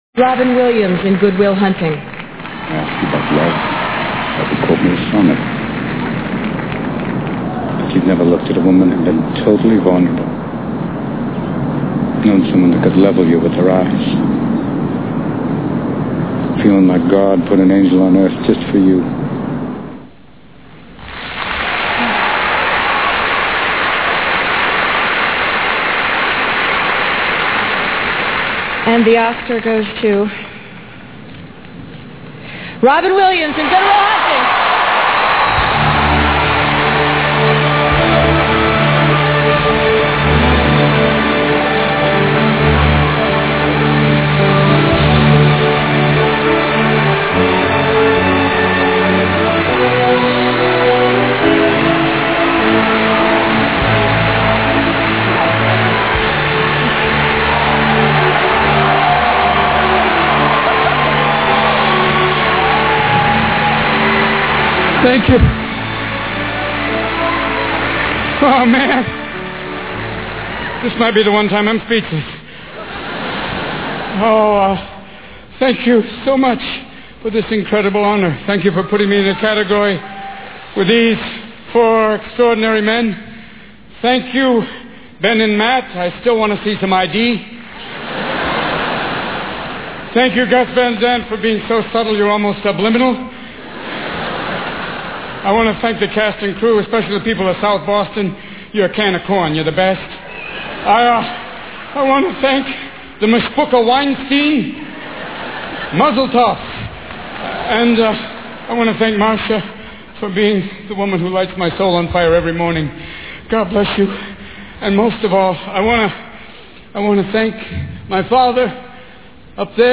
Here's Robin Williams' acceptance speech for the Academy Award ('Supporting Actor'):
robinspeech.mp3